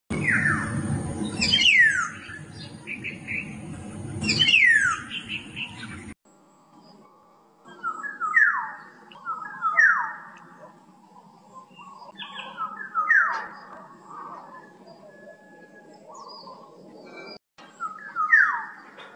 黄鹂鸣叫声